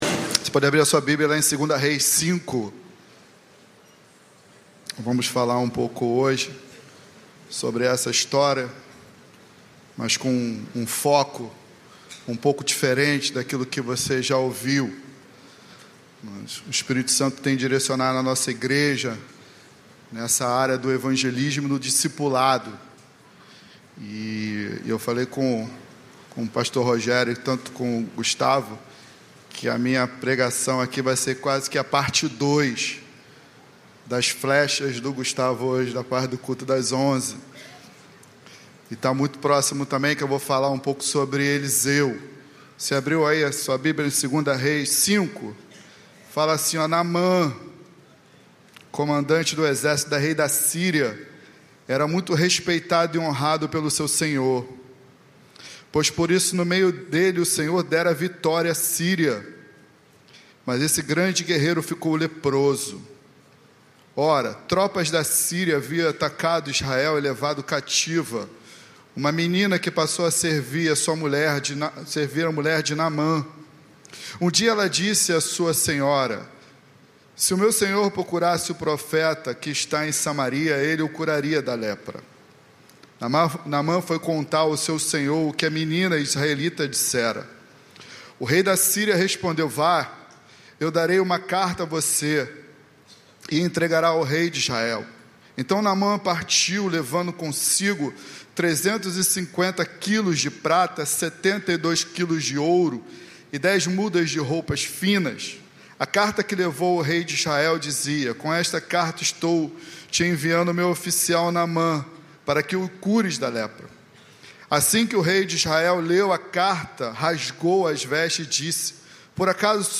Mensagem
na Igreja Batista do Recreio.